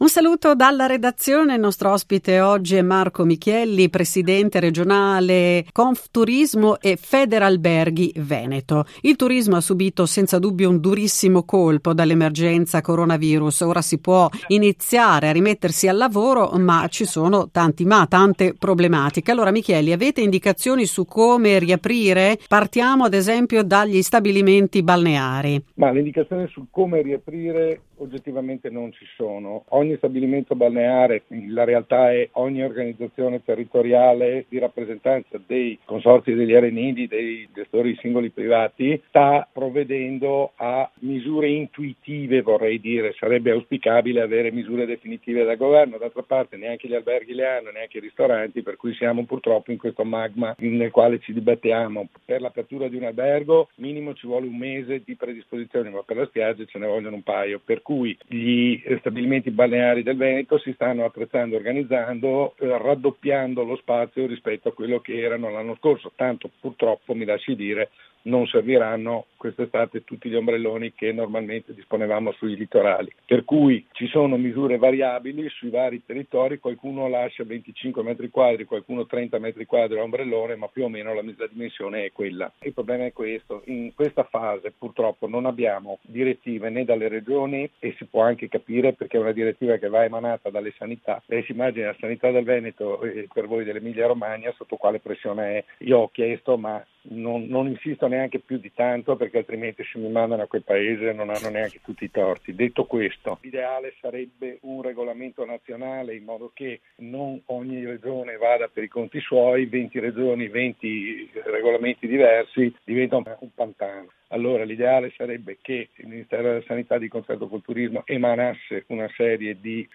La nostra redazione ha intervistato